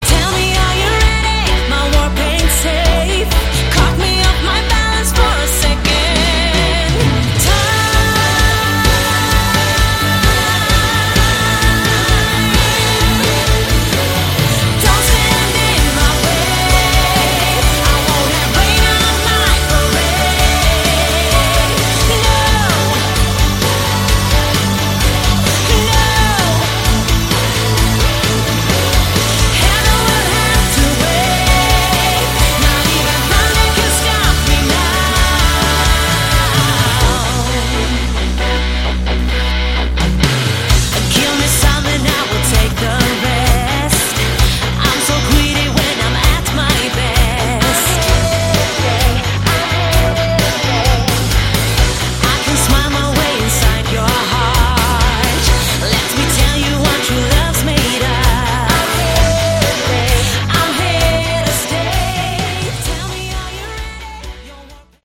Category: Melodic Rock
lead and backing vocals
guitars
drums, keyboards
bass